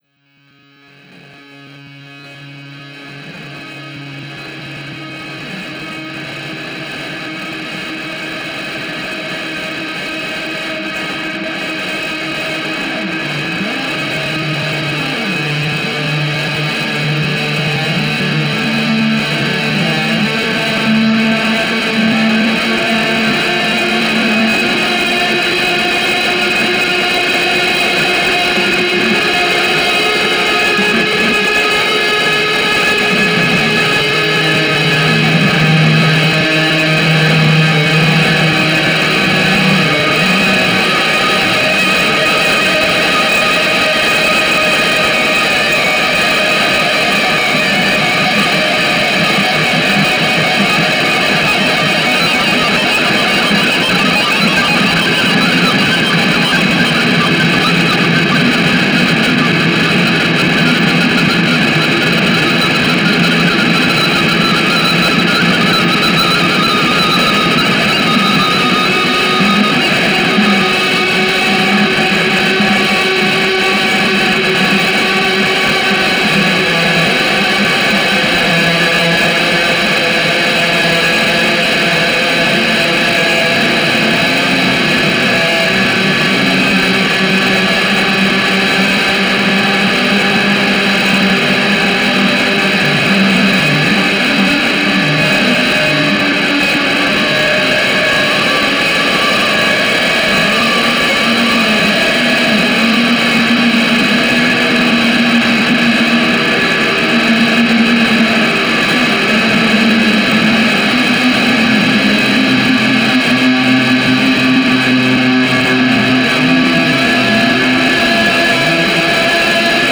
これは、2012年3月10日（土）におこなわれた演奏会の実況録音です。
モロに生の生々しい音！
演奏会は最大120dBの強烈な爆音でおこなわれました。
録音は完全にステレオ・マイクによる収録のみでおこなわれ、ライン信号は一切使用されていません。
いずれも、その夜、演奏されたままの音で、編集時の加工は音質補正のみ。
オーバーダビングなどはなく、また演奏時にもサンプラーなどの使用は皆無です。